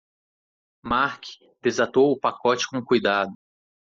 Pronounced as (IPA) /paˈkɔ.t͡ʃi/